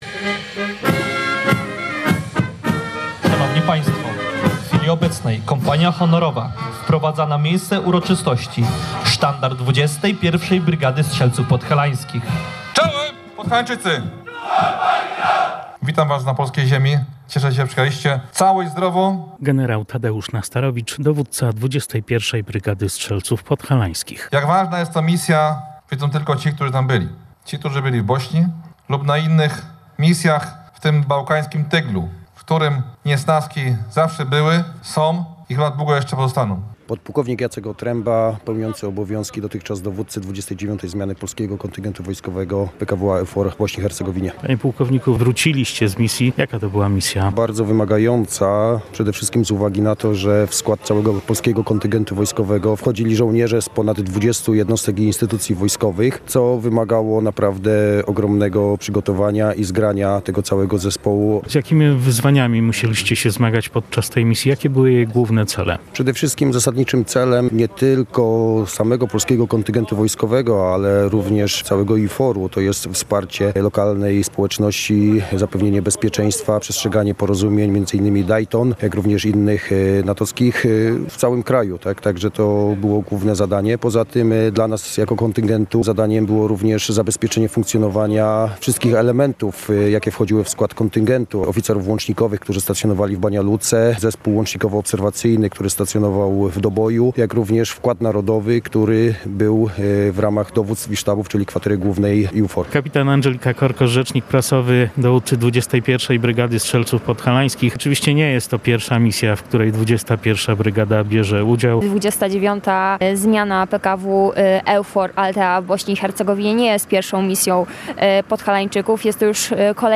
Żołnierze 21 Brygady Strzelców Podhalańskich wrócili z misji w Bośni i Hercegowinie [ZDJĘCIA] • Relacje reporterskie • Polskie Radio Rzeszów
Relacje reporterskie • W Rzeszowie powitano 30 żołnierzy 21 Brygady Strzelców Podhalańskich, którzy zakończyli służbę w ramach XXIX zmiany misji Polskiego Kontyngentu Wojskowego Althea w Bośni i Hercegowinie.